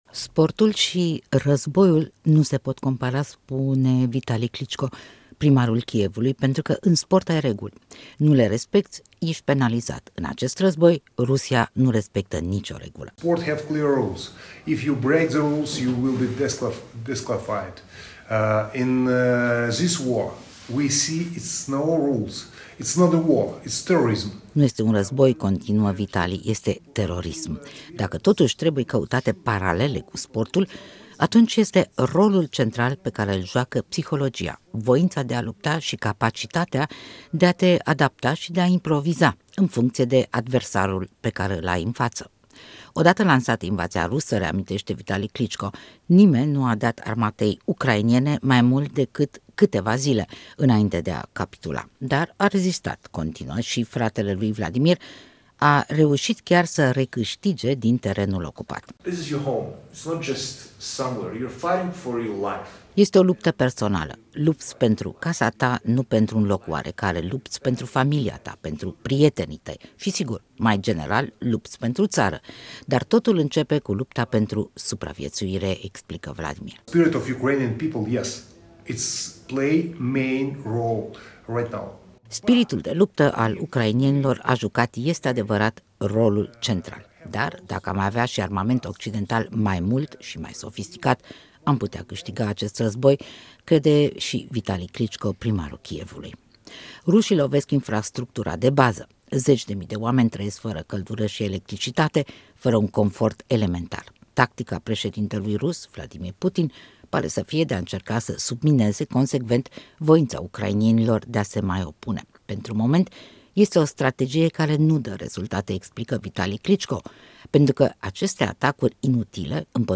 Interviu cu Vitali și Vladimir Kliciko